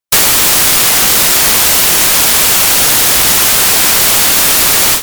SE（長いノイズ）
長いノイズ。ザーーーー。